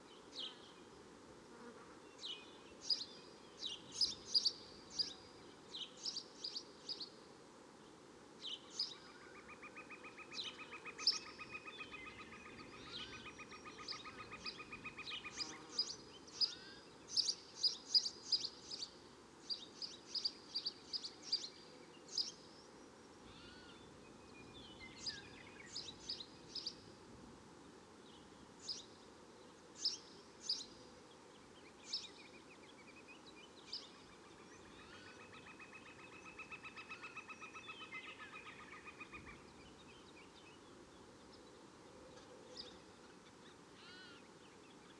swamp-and-jungle-birds.f3a8604e.wav